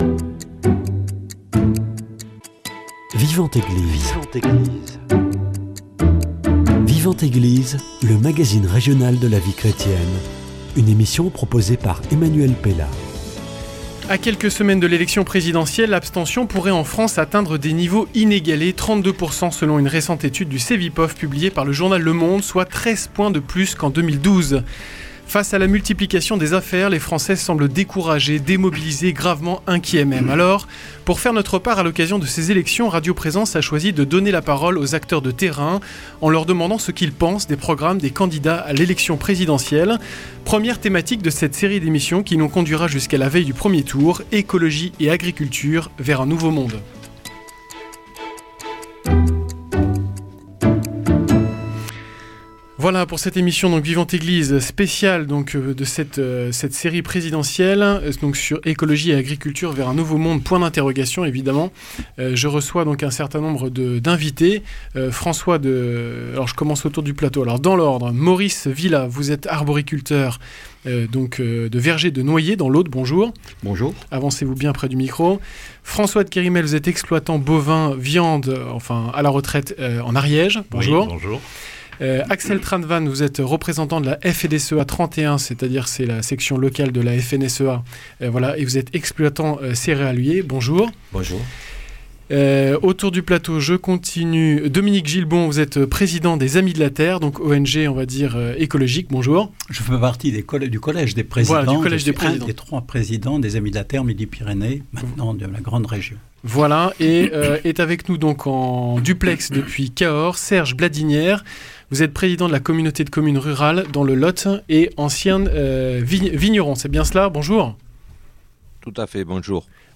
Alors pour faire notre part à l’occasion de ces élections, radio Présence a choisi de donner la parole aux acteurs de terrain, en leur demandant ce qu’ils pensent des programmes des candidats à l’élection présidentielle.